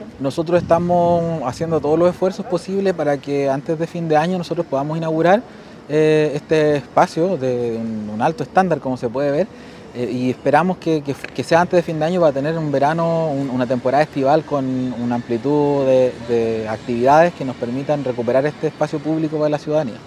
Fabián Nail, Seremi de la Vivienda de la región de Los Lagos, señaló que se está trabajando para que antes de fin de año se pueda inaugurar dicha obra.